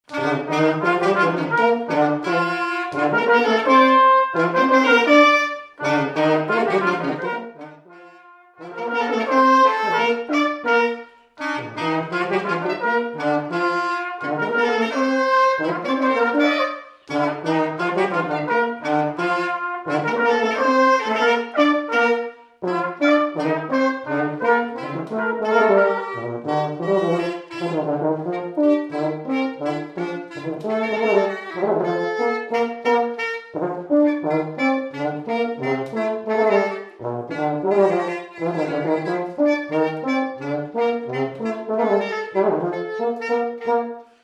Chants brefs - A danser
Résumé instrumental
danse : polka
Pièce musicale inédite